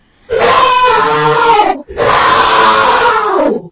На этой странице собраны звуки момо — необычные и тревожные аудиоэффекты, которые подойдут для творческих проектов.
РЕЗКИЙ КРИК МОМО